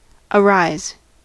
Ääntäminen
US : IPA : [əˈrɑɪz]